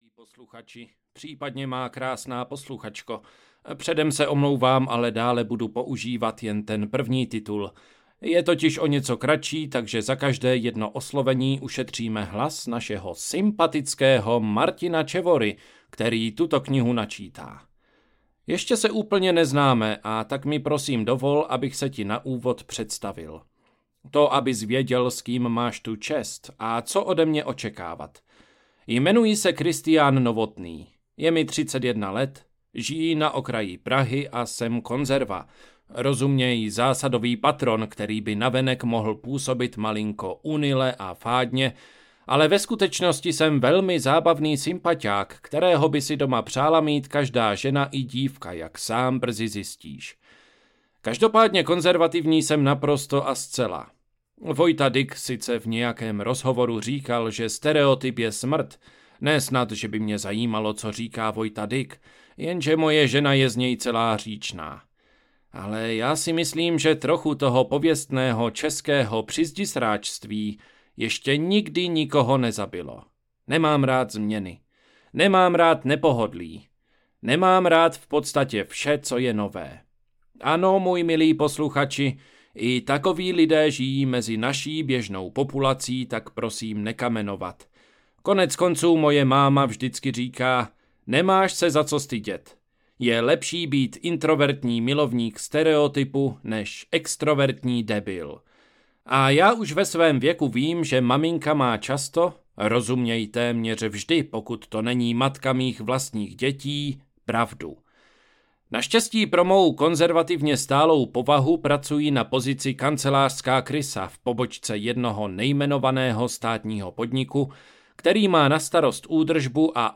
Můj život s třemi ženami audiokniha
Ukázka z knihy